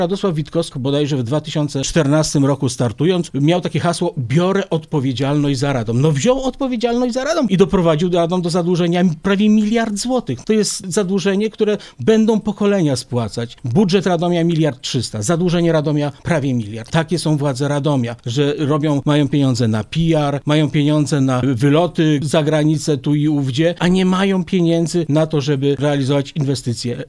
Ten temat został poruszony przez senatora PiS , Wojciecha Skurkiewicza podczas Mocnej Rozmowy na antenie Radia Radom.